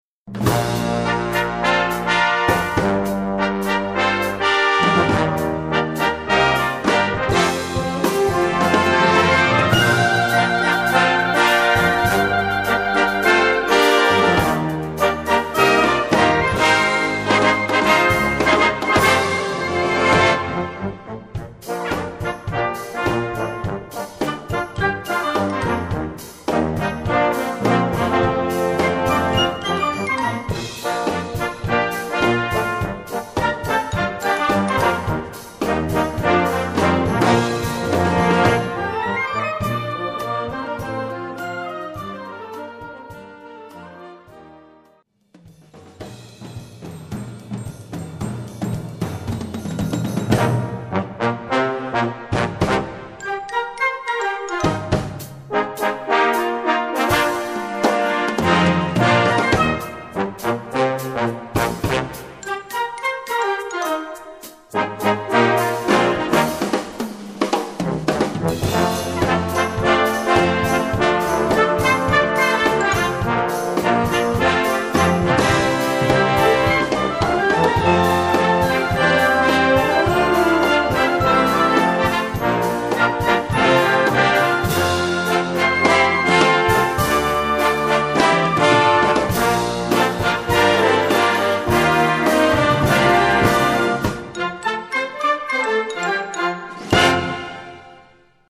Gattung: Open Air Music
Besetzung: Blasorchester